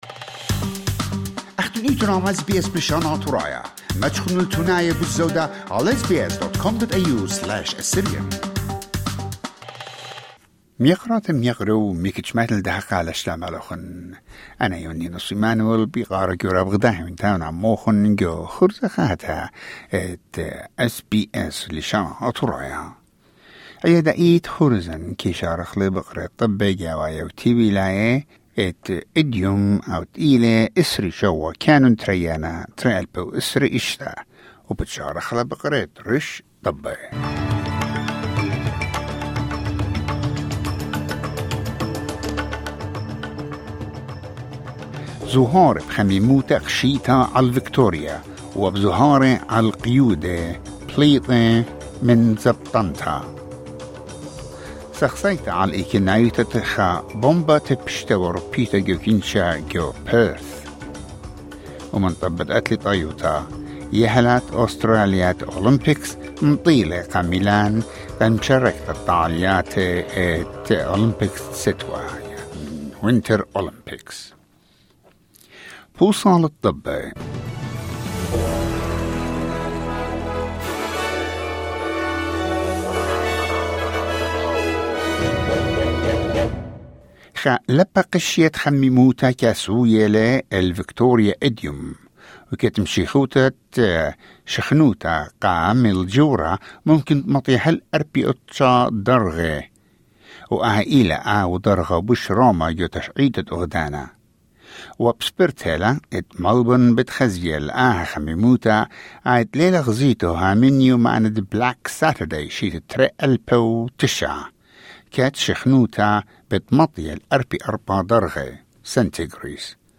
News Bulletin 27 January 2026